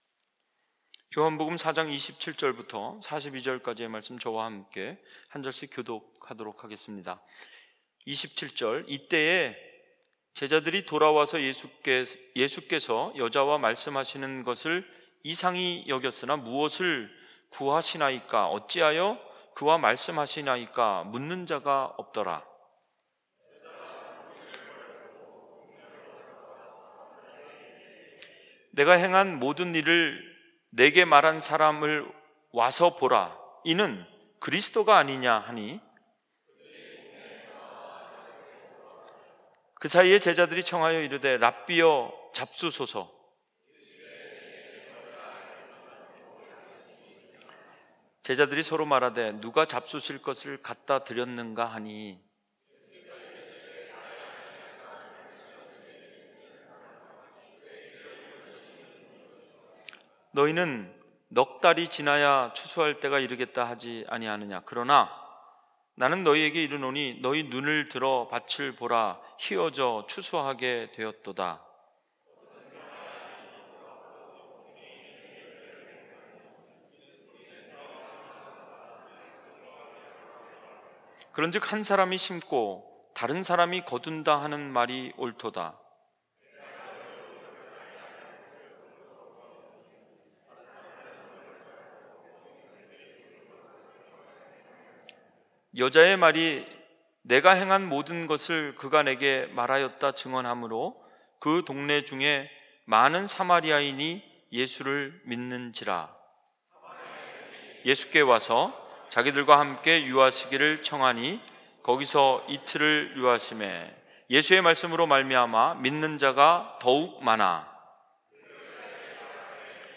주일설교 - 2019년 04월14일 - 세상의 구주신 줄 앎이라 (The savior of the world)